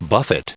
buffet_en-us_recite_stardict.mp3